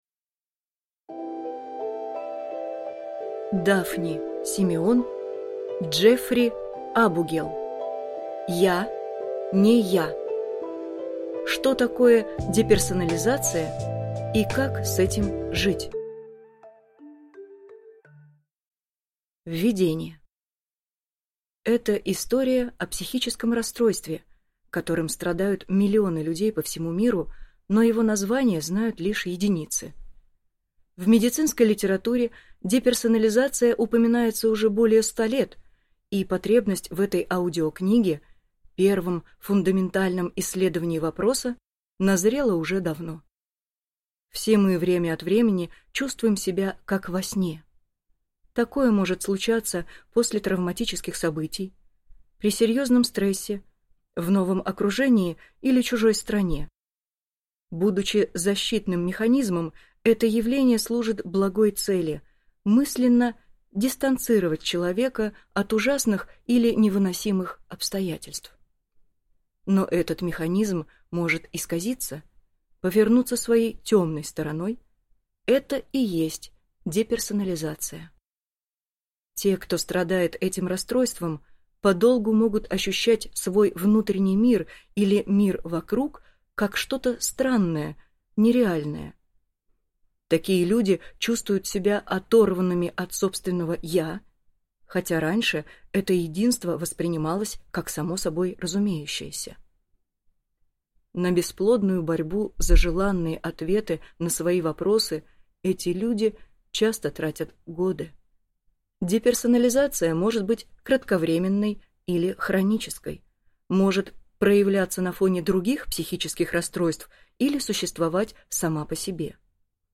Аудиокнига Я не я. Что такое деперсонализация и как с этим жить | Библиотека аудиокниг